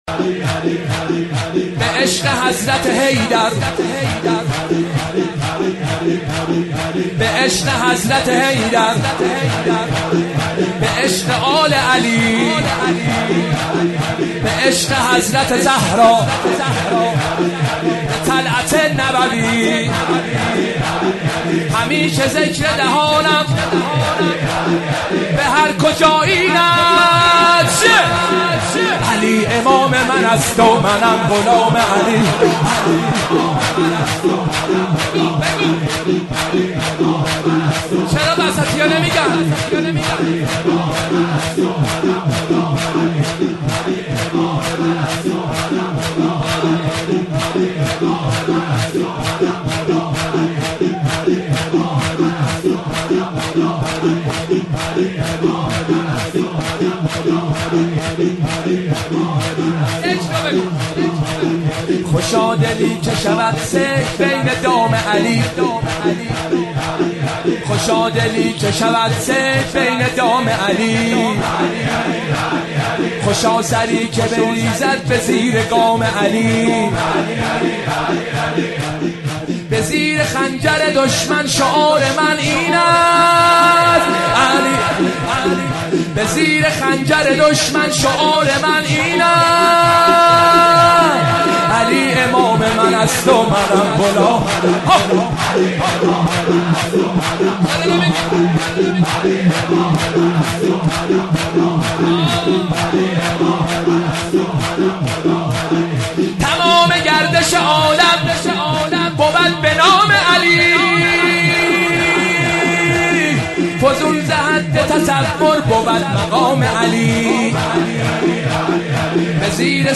شب دوم محرم
شور